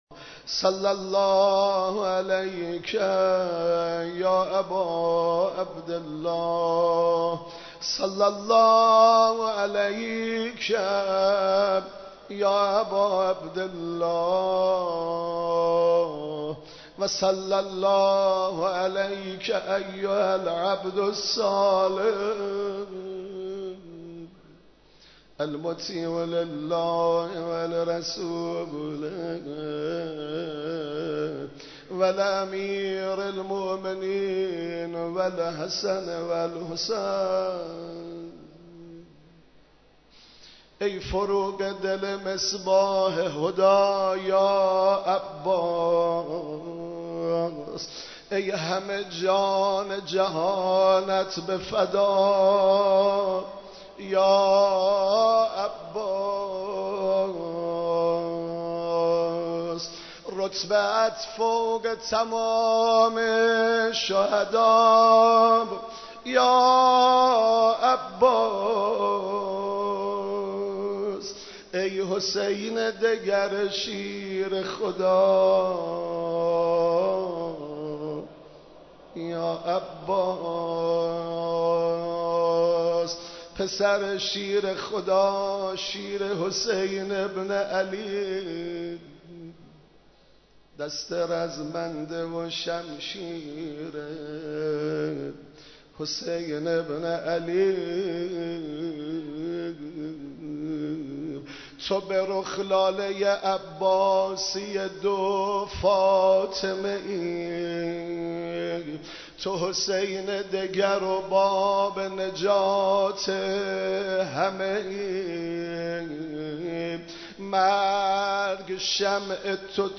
مراسم عزاداری شب تاسوعای حسینی علیه‌السلام
مداحی